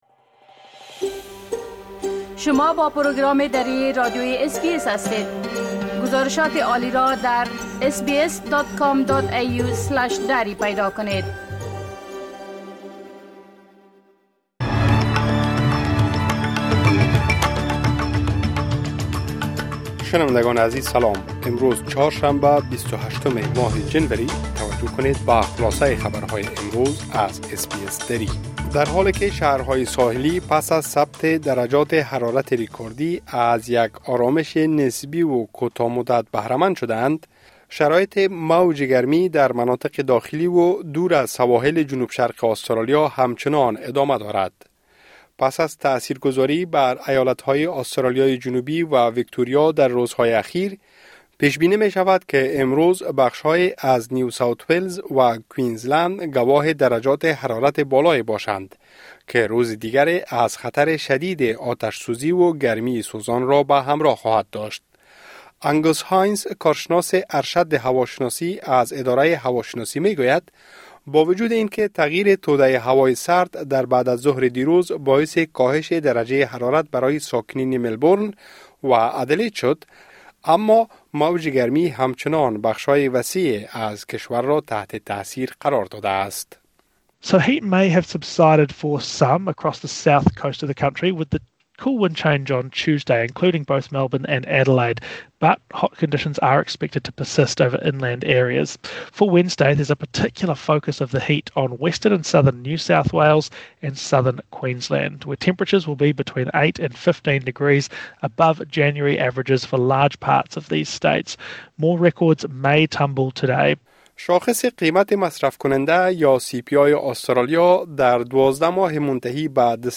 خلاصه‌ای مهم‌ترين خبرهای روز | ۲۸ جنوری